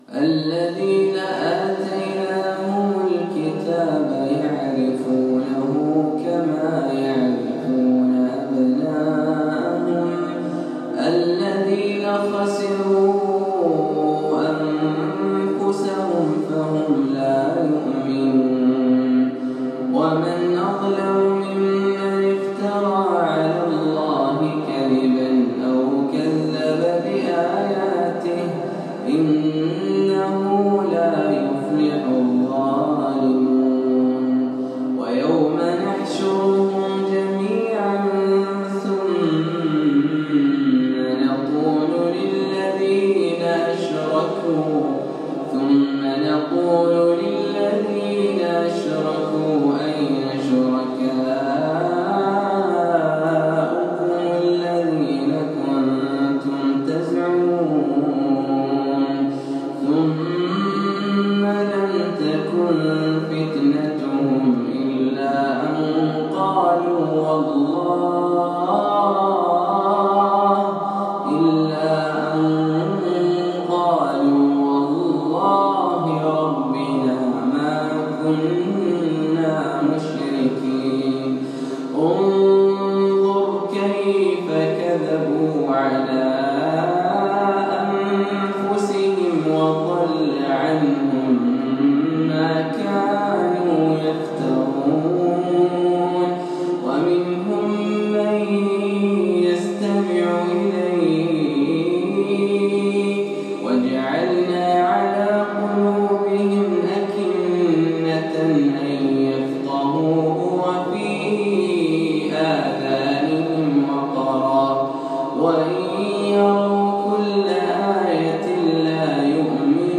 تلاوة هادئة خاشعة من سورة الأنعام
مسجد عبدالرحمن الداخل ، الرياض